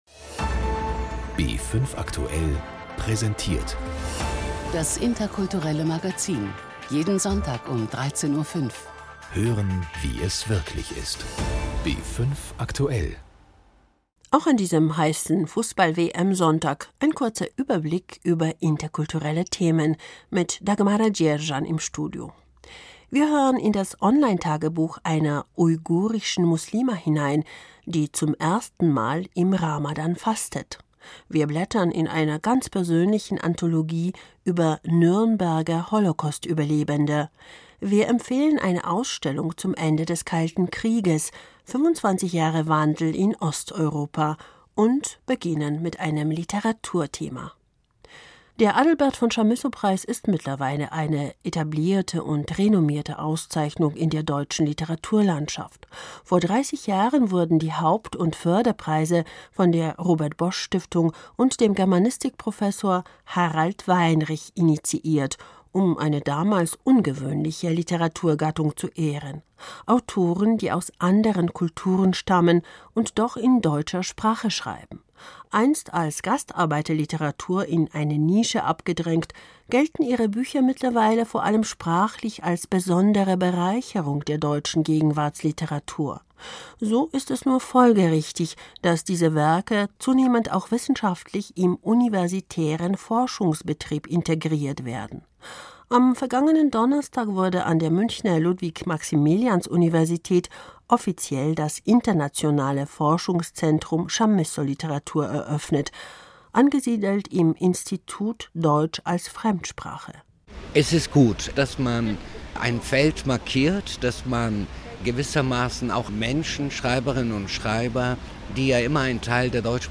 Der Bayerische Rundfunk hat die schönsten Impressionen des Abends festgehalten und unseren Imagefilm finden Sie hier .